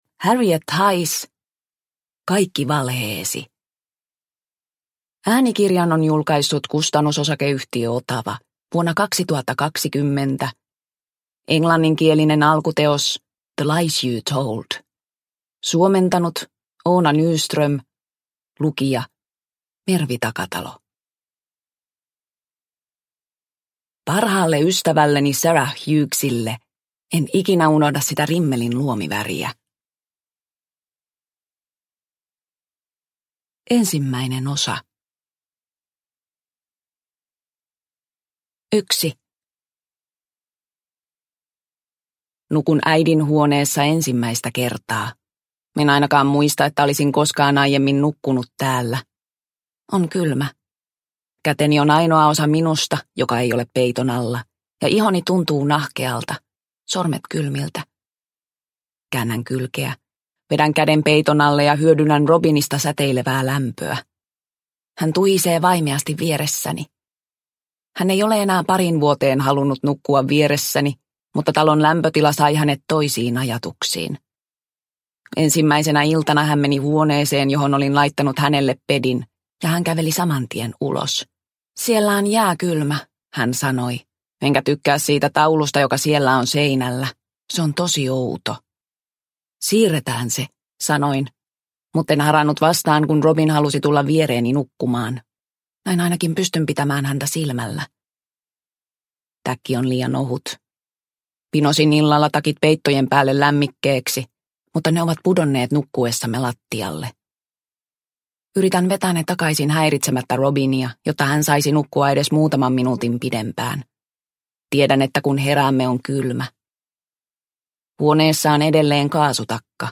Kaikki valheesi – Ljudbok – Laddas ner